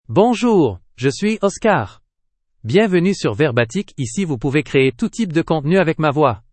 MaleFrench (Canada)
Oscar is a male AI voice for French (Canada).
Voice sample
Listen to Oscar's male French voice.
Male